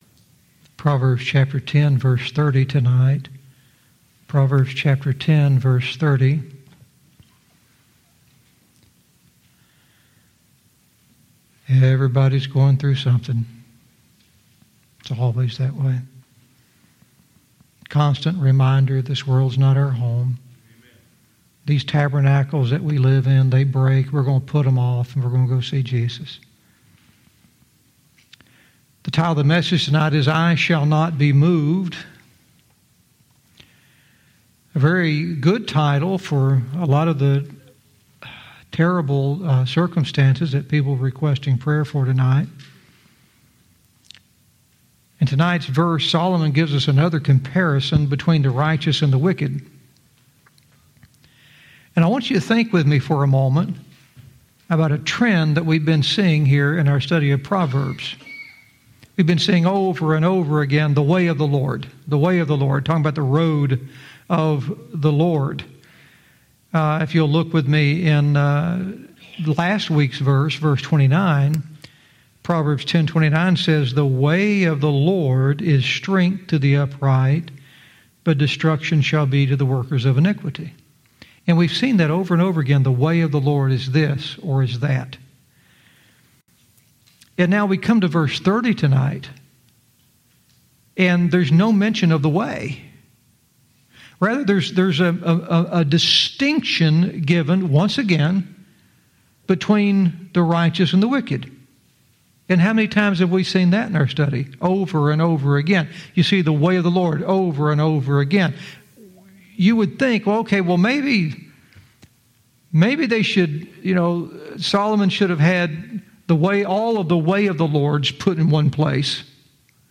Verse by verse teaching - Proverbs 10:30 "I Shall Not Be Moved"